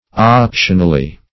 \Op"tion*al*ly\